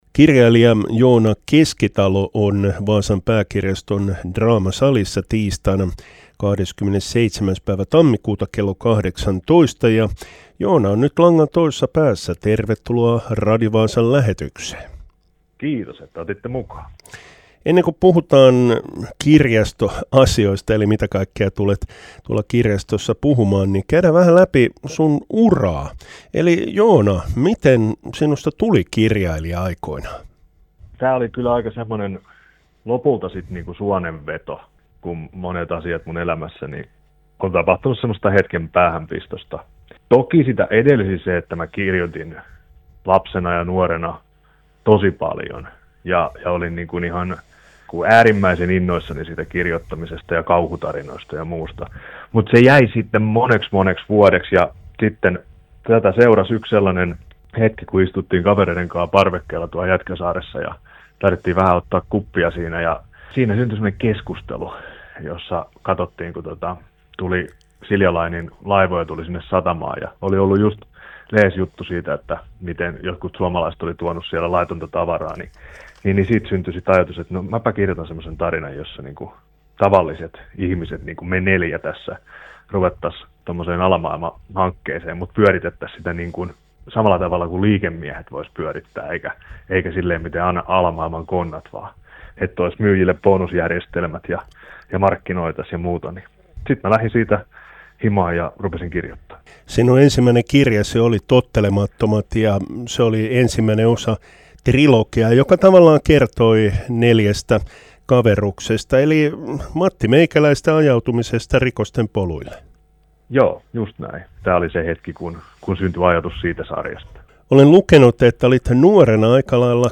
haastattelussa.